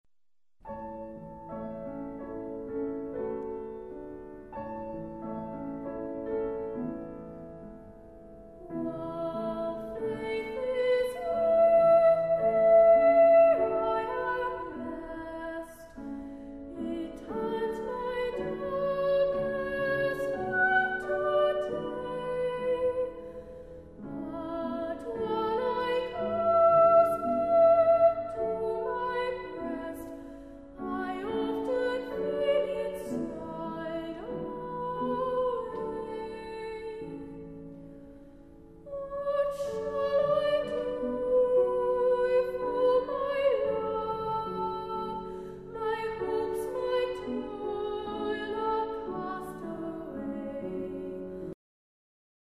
Unison